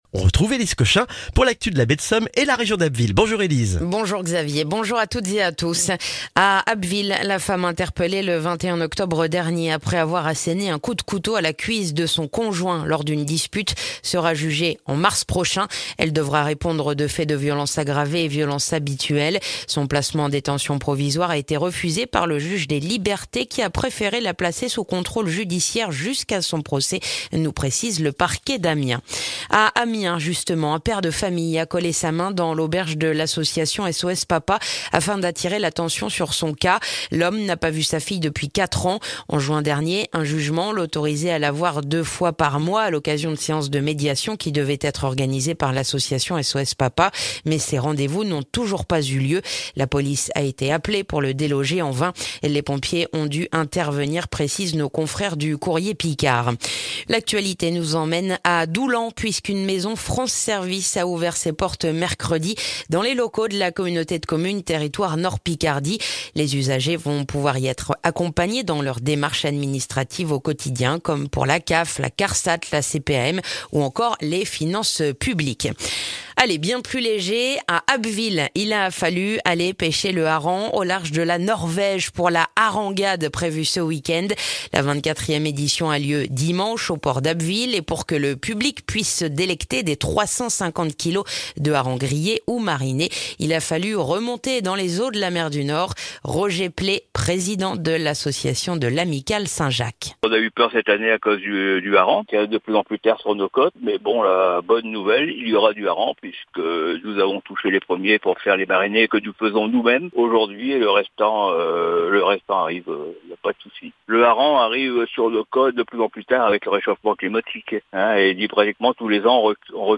Le journal du vendredi 4 novembre en Baie de Somme et dans la région d'Abbeville